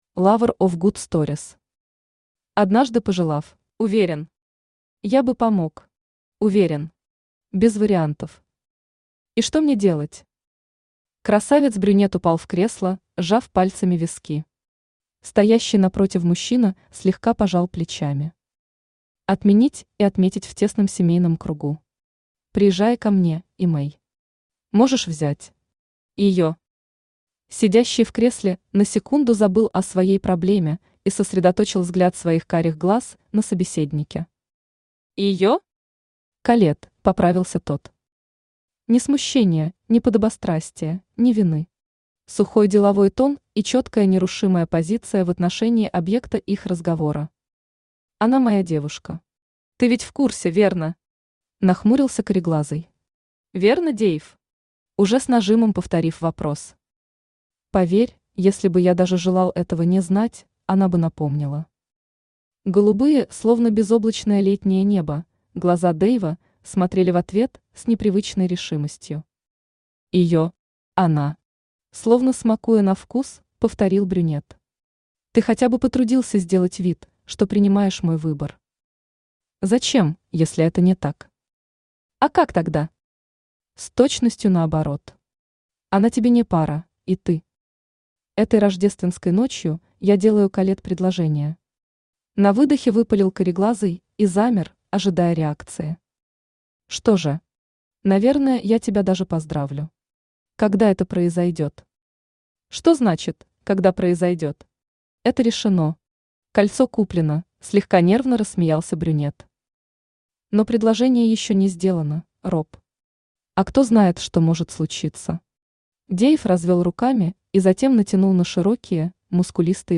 Aудиокнига Однажды пожелав Автор Lover of good stories Читает аудиокнигу Авточтец ЛитРес.